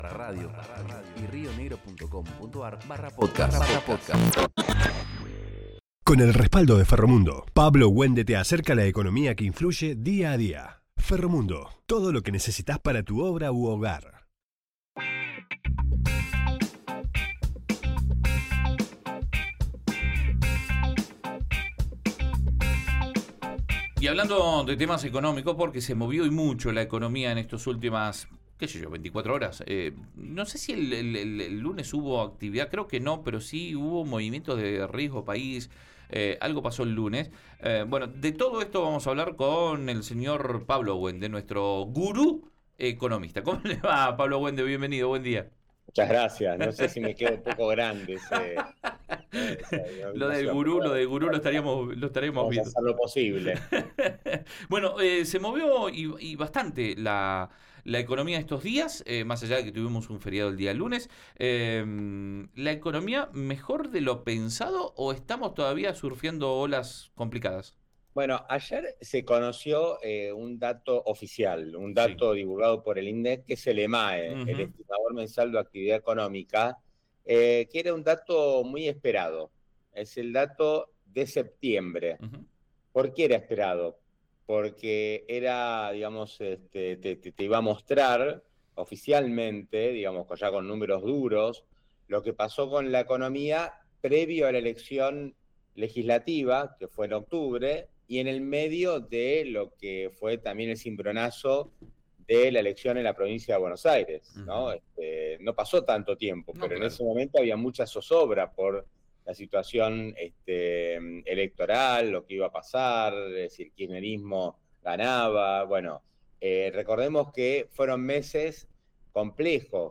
En su columna en Río Negro Radio